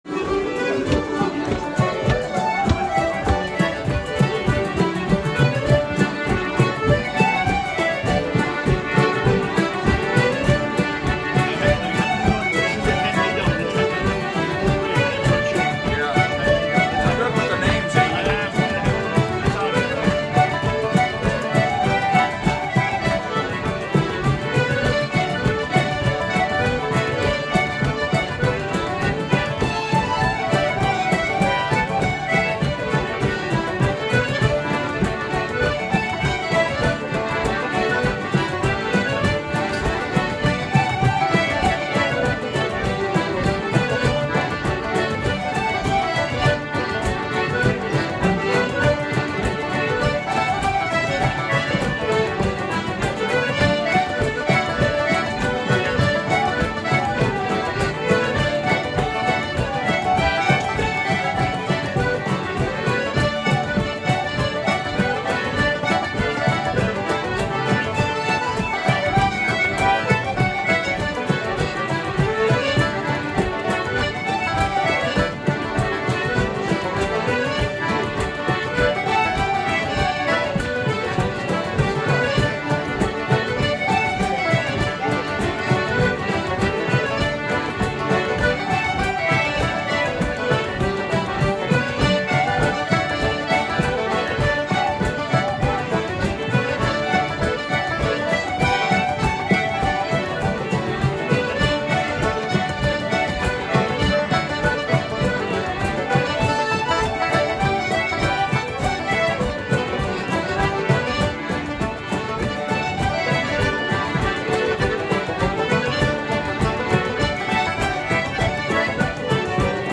Who says traditional Irish music is dead???
Irish Arts Weekend
Here's just one of the great sets we played